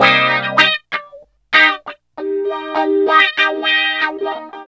74 GTR 2  -L.wav